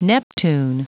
Prononciation du mot neptune en anglais (fichier audio)
Prononciation du mot : neptune